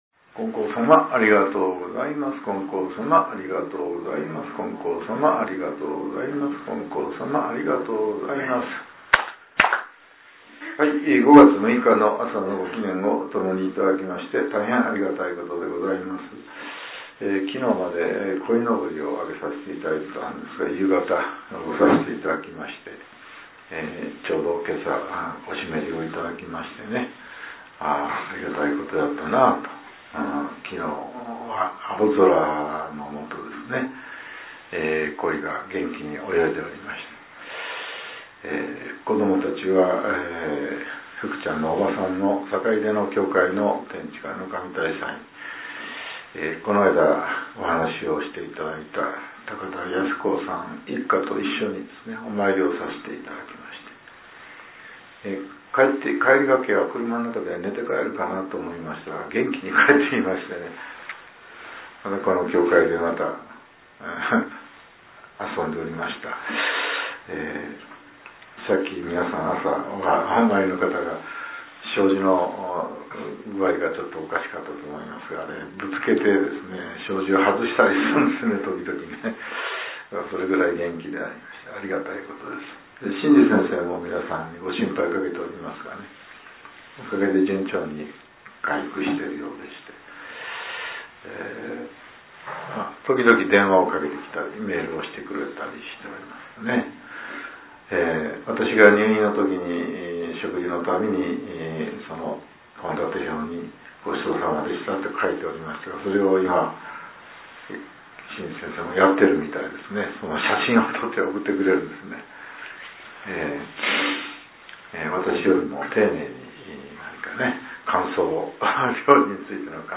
令和７年５月６日（朝）のお話が、音声ブログとして更新されています。